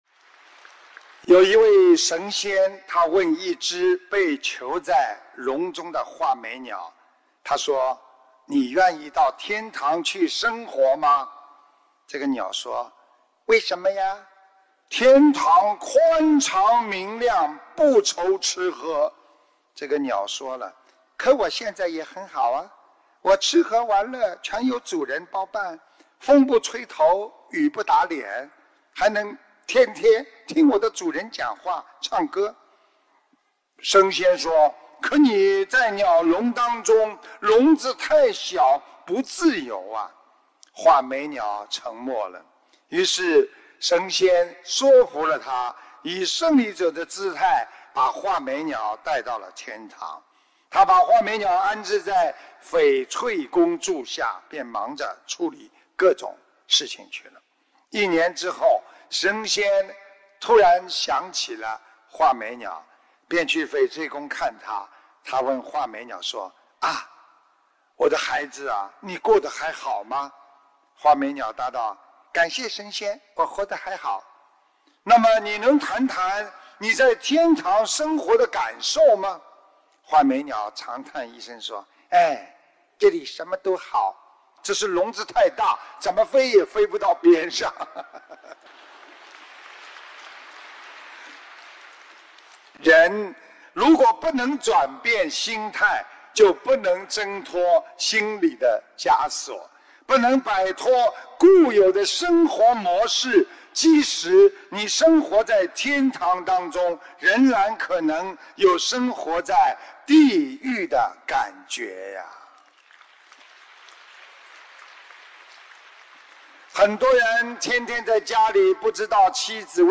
音频：囚在笼中的画眉鸟！布里斯本世界佛友见面会！